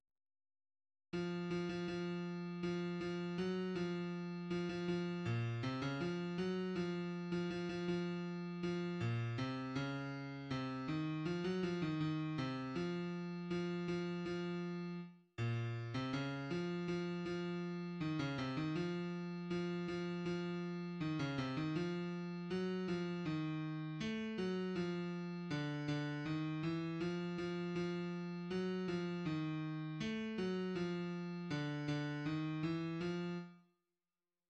{ \clef bass \tempo 4=80 \key des \major \time 2/4 \set Score.currentBarNumber = #1 \bar "" r4 r8 f8 f16 f16 f4 f8 f ges f4 f16 f f8 bes, c16 des f8 ges f8. f16 f16 f16 f4 f8 bes, c des4 c8 ees f16 ges f ees ees8 c f4 f8 f f4 r8 bes,~ bes,16 c des8 f f f4 ees16 des c ees f4 f8 f f4 ees16 des c ees f4 ges8 f ees4 a8 ges f4 d8 d ees e f f f4 ges8 f ees4 a8 ges f4 d8 d ees e f r r4 } \addlyrics {\set fontSize = #-2 doggy doogy } \midi{}